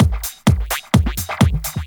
Electrohouse Loop 128 BPM (34).wav